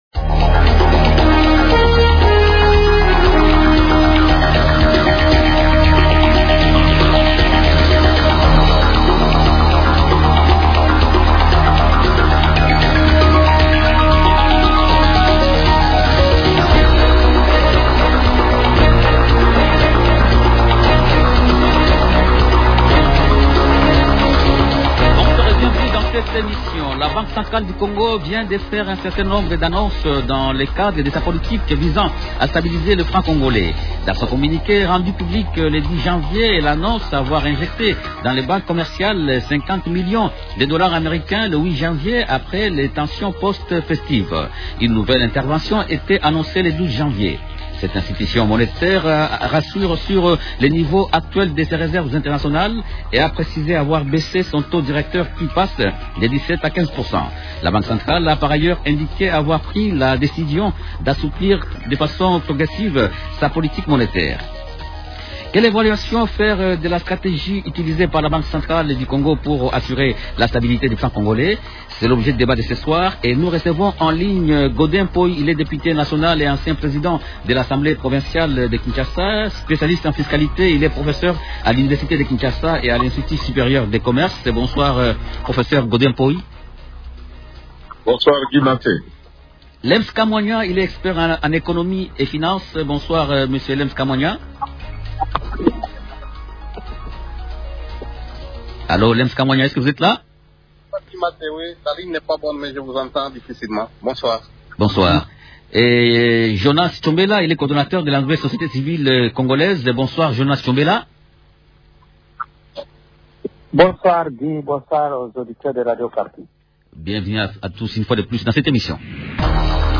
Question : -Quelle évaluation faire de la stratégie utilisée par la Banque centrale du Congo pour assurer la stabilité du franc congolais ? Invités : -Godé Mpoy, député national et ancien président de l’assemblée provinciale de Kinshasa.
expert en économie et Finances.